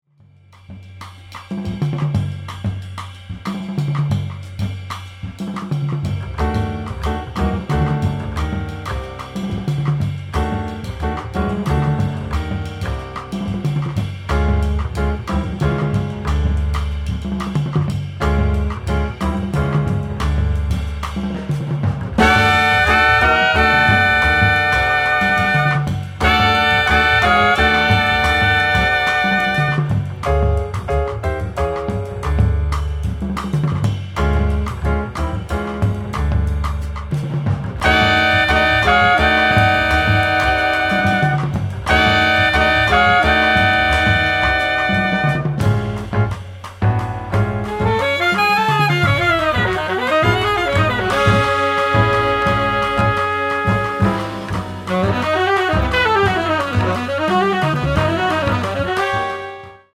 alto & tenor saxophone
piano
bass
drums